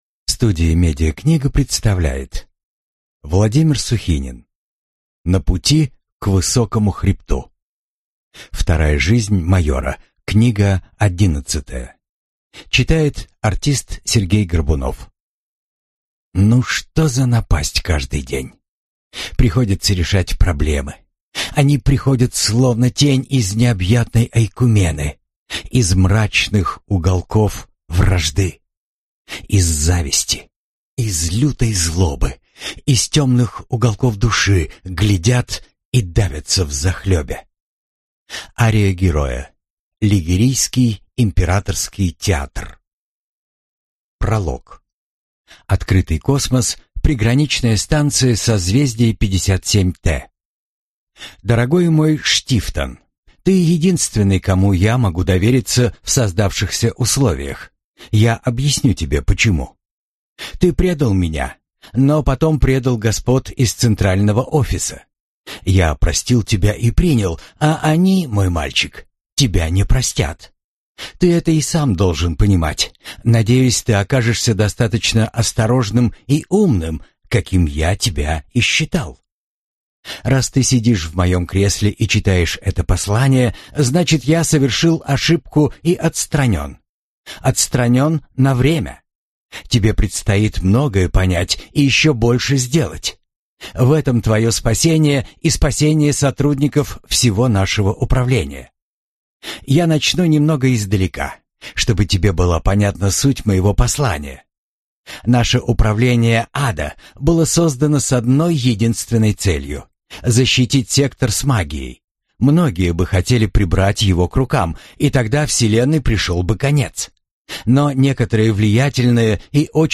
Аудиокнига На пути к Высокому хребту | Библиотека аудиокниг